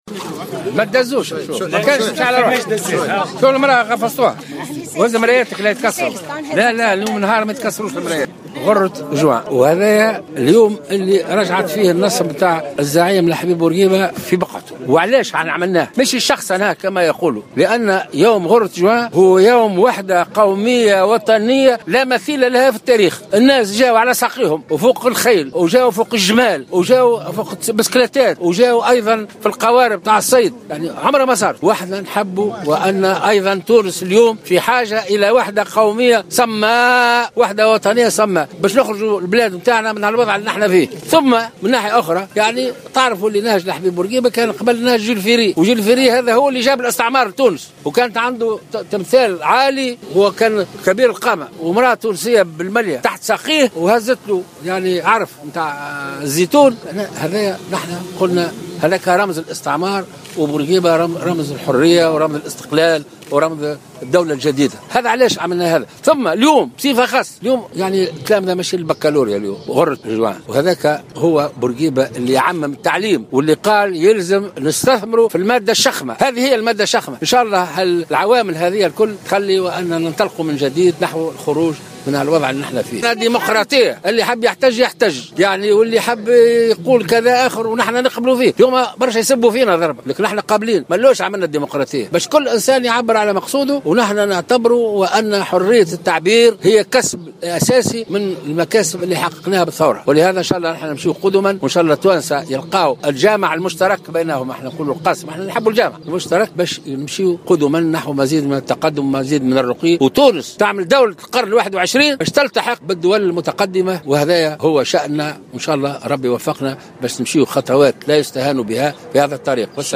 قال رئيس الجمهورية الباجي قائد السبسي خلال إشرافه اليوم الأربعاء غرة جوان 2016 على تدشين تمثال الزعيم الحبيب بورقيبة وسط العاصمة إن هذا اليوم الذي عاد فيه نصب الزعيم إلى مكانه ليس للشخصنة مثلما يروج له وإنما لأنه يوم وحدة قومية وطنية لا مثيل لها في التاريخ.